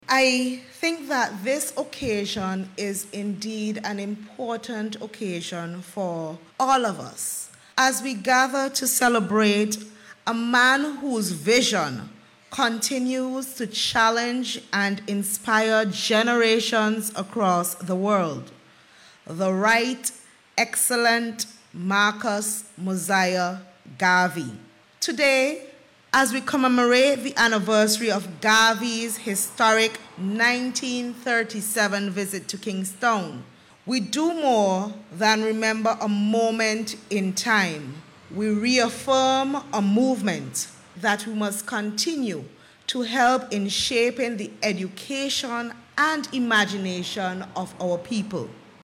A symposium honouring the life and legacy of Marcus Garvey, a prominent civil rights leader, was held on Monday.
In her remarks, Permanent Secretary in the Ministry of Tourism and Culture, Dr. Tamira Browne underscored the importance of the symposium.